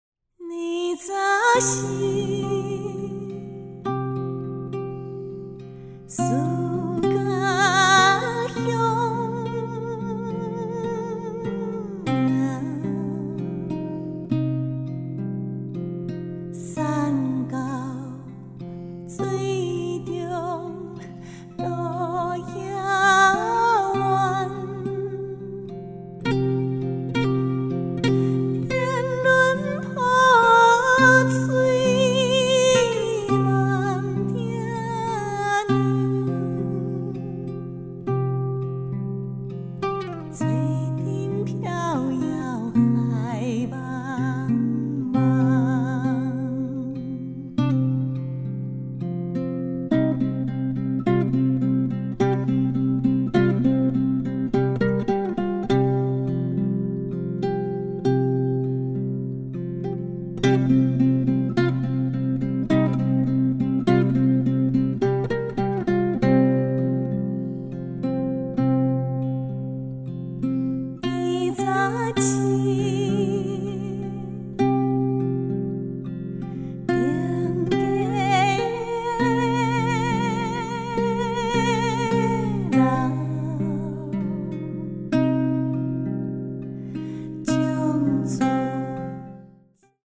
現代南管